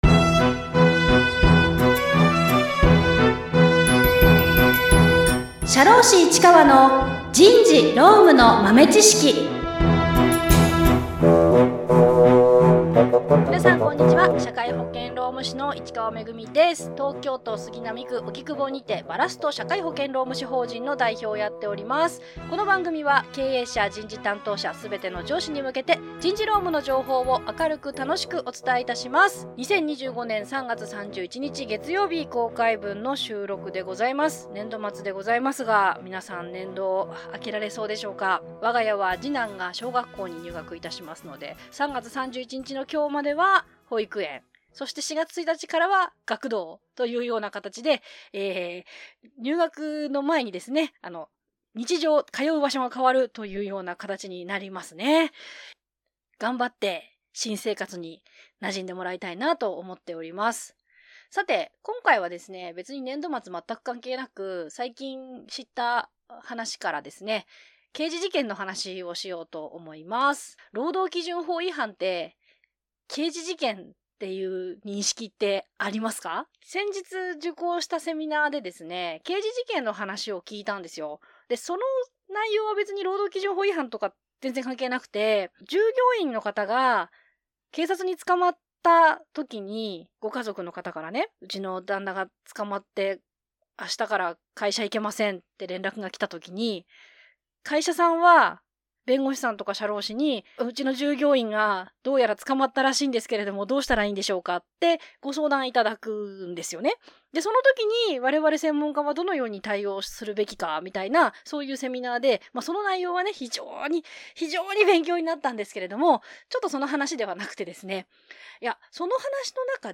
経営者、人事部、すべての上司に向けて、社会保険労務士が、「働き方改革」「社会保険」「労使トラブル」など最近の人事労務の話題をおしゃべりしています。